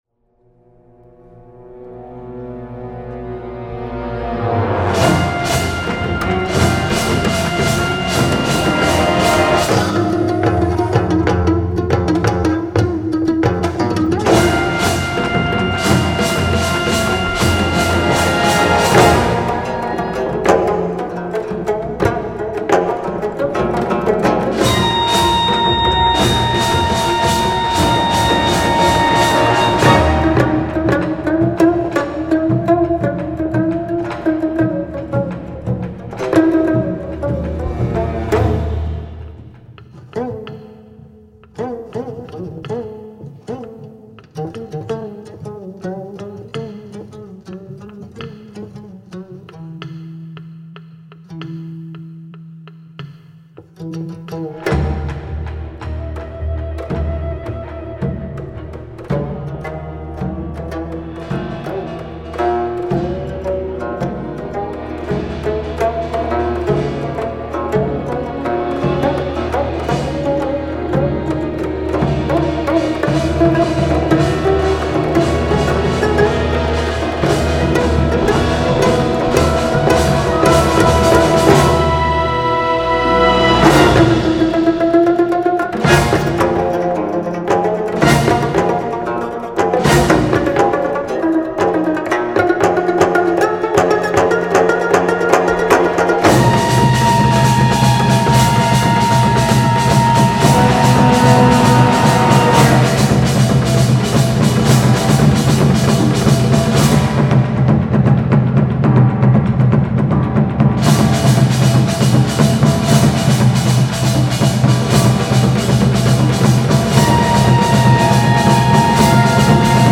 concerto for 9-string geomungo & orchestra